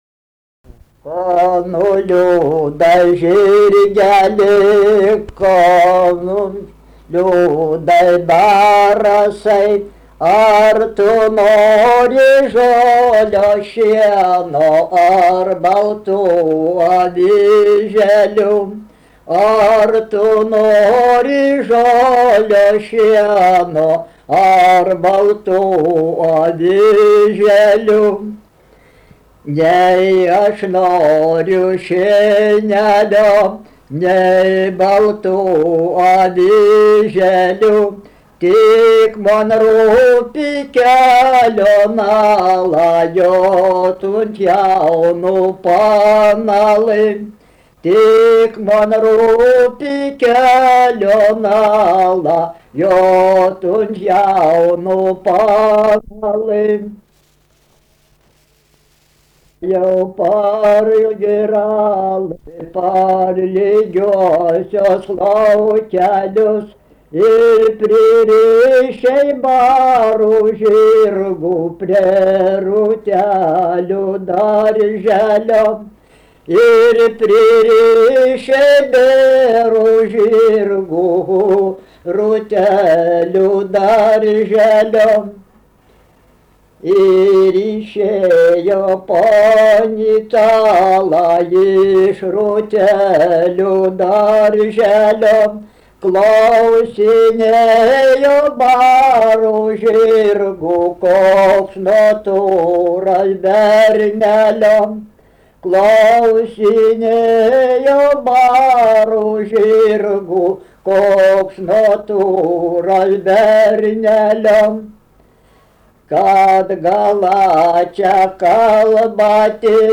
daina, vestuvių
Erdvinė aprėptis Bukonių k.
Atlikimo pubūdis vokalinis